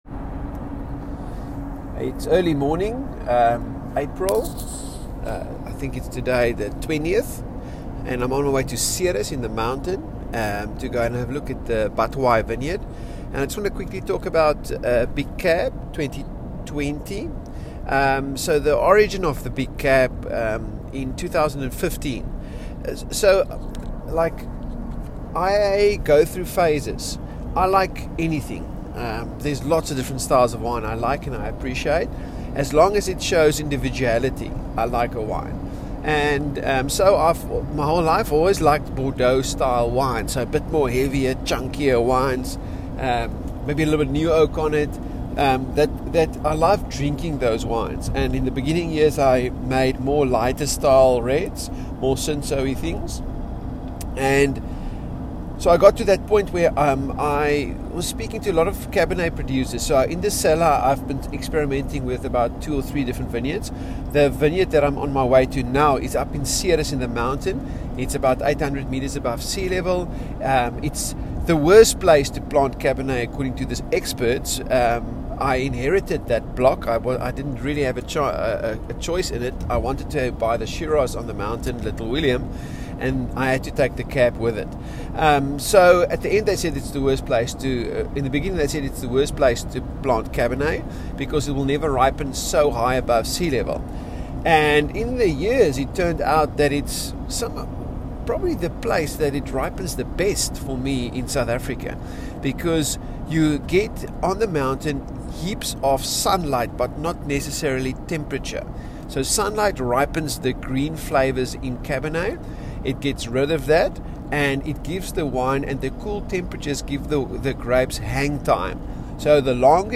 Voice Recording: